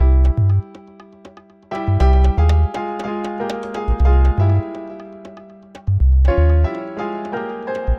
拉丁舞曲与钢琴和低音
描述：阿诺、贝斯、鼓
Tag: 120 bpm Chill Out Loops Groove Loops 1.35 MB wav Key : Unknown